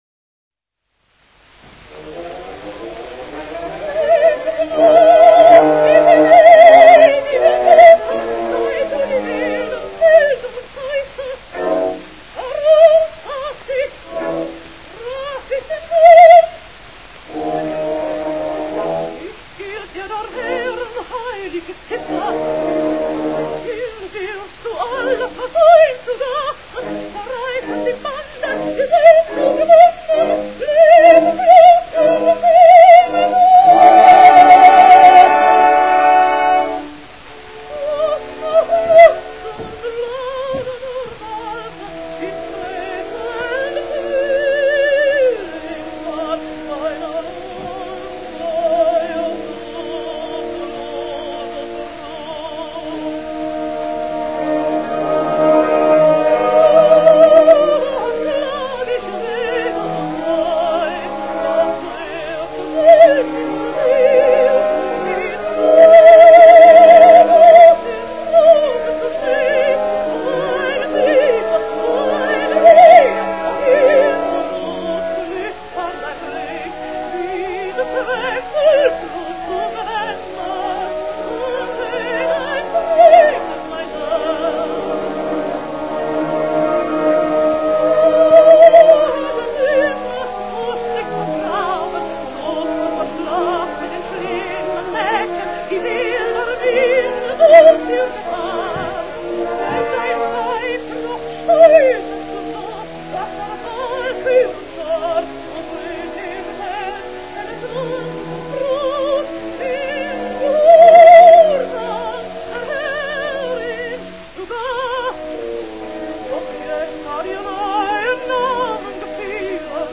German contralto, 1885 - 1971
Margarethe Arndt-Ober is one of the most beautiful contralto voices on recordings.
She is a singer with a wonderful dramatic voice of an extraordinary steadiness throughout the whole range. Her singing is characterized by intensity and vitality.